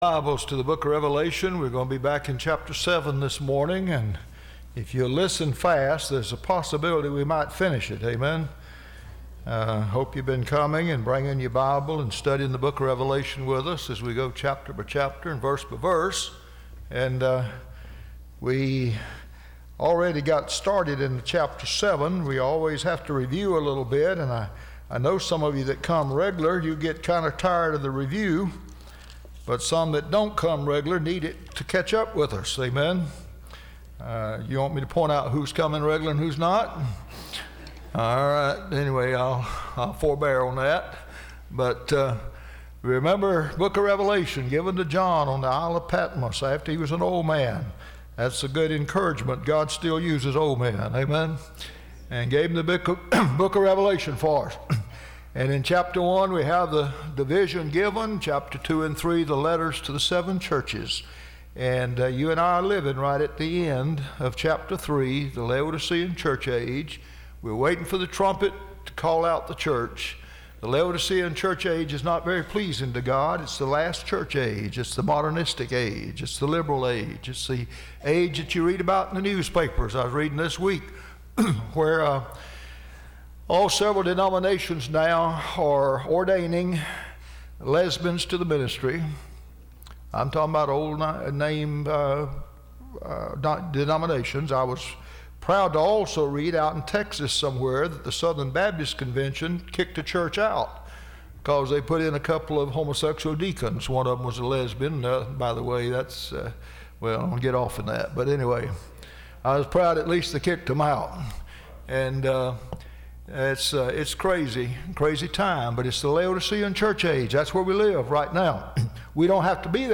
Service Type: Sunday School